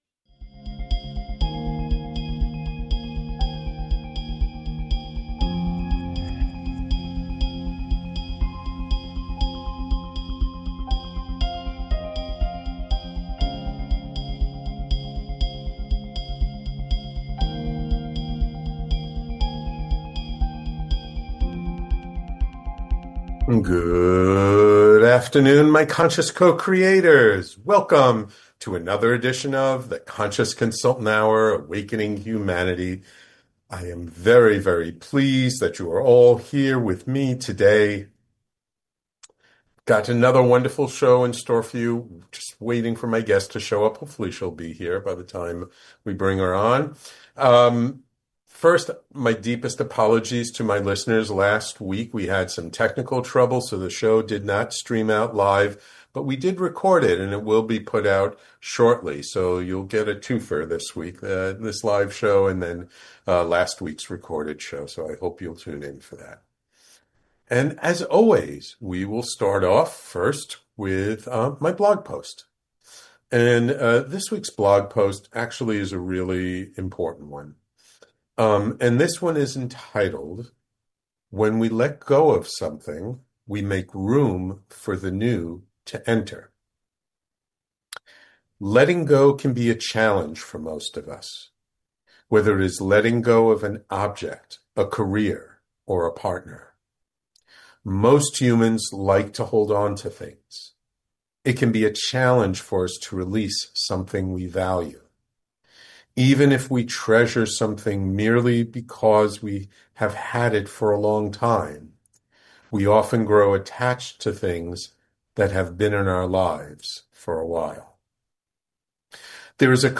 In this insightful conversation